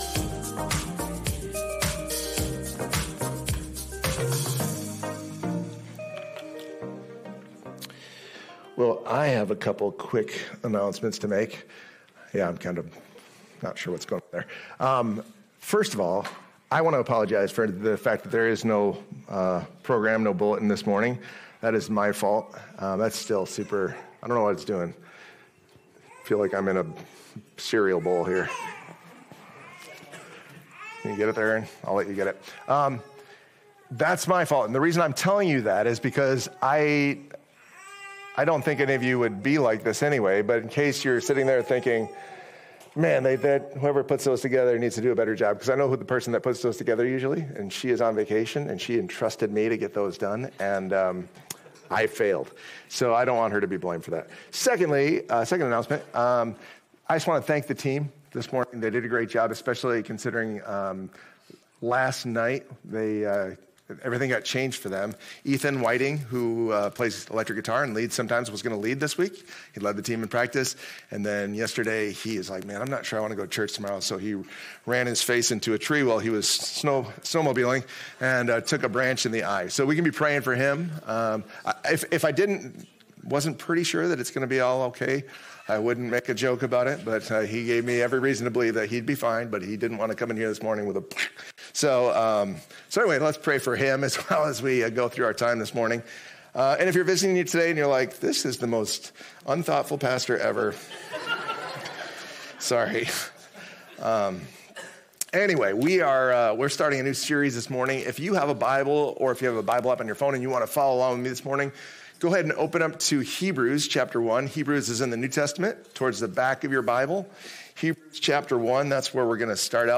Sermons | New Hope Church